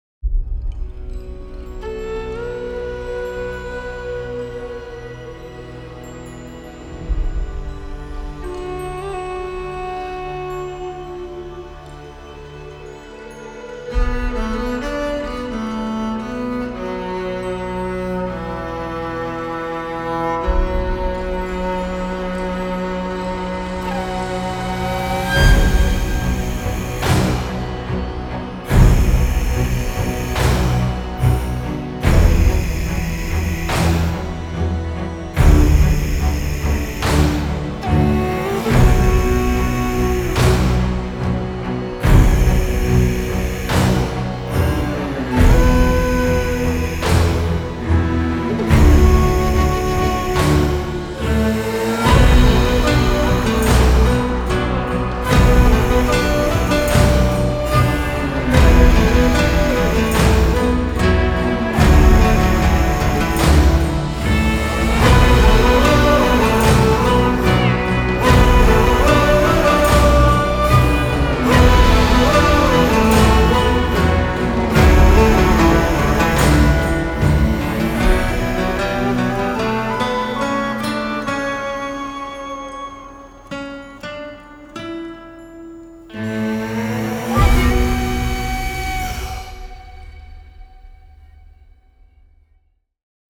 suspense Version